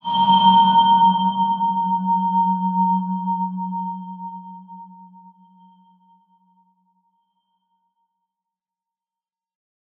X_BasicBells-F#1-mf.wav